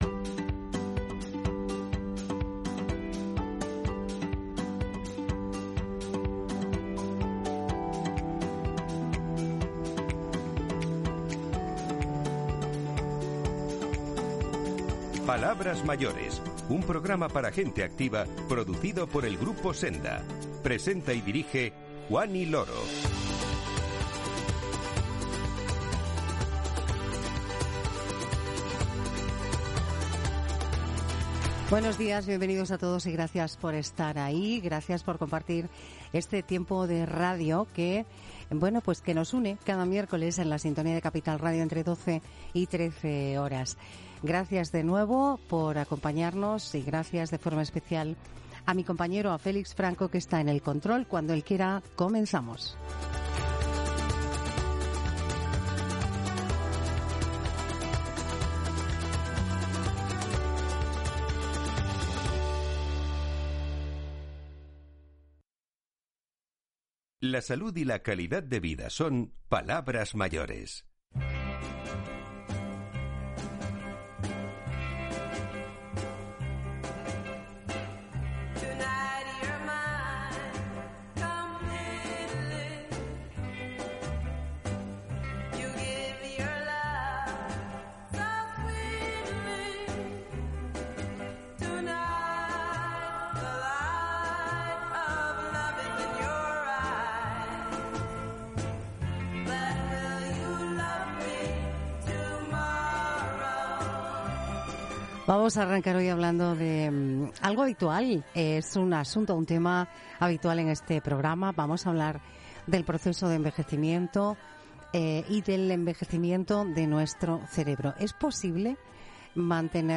Música para cerrar